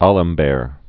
(ăləm-bâr, ä-läɴ-bĕr), Jean Le Rond d' 1717-1783.